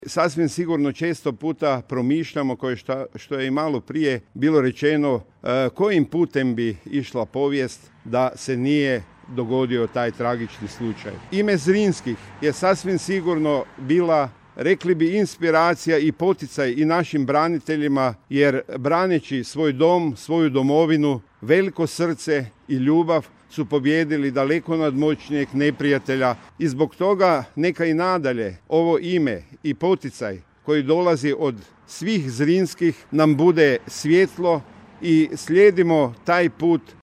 Zamjenik župana Međimurske županije Josip Grivec: